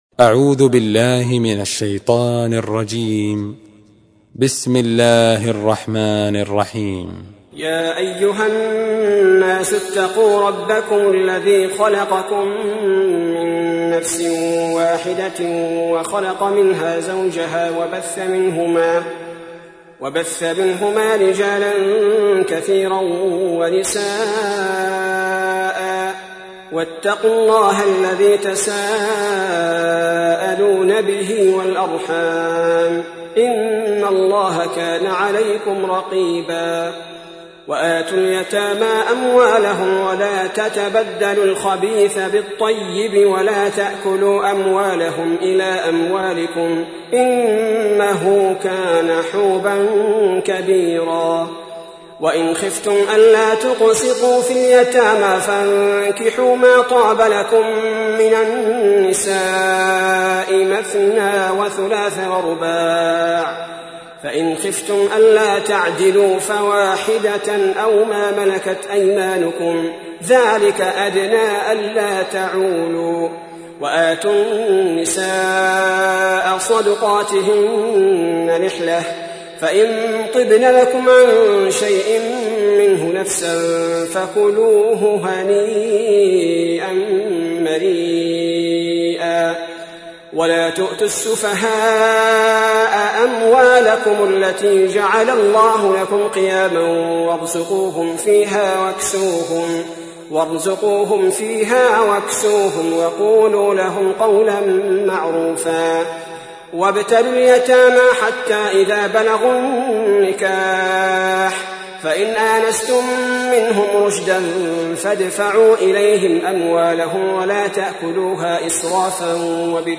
تحميل : 4. سورة النساء / القارئ عبد البارئ الثبيتي / القرآن الكريم / موقع يا حسين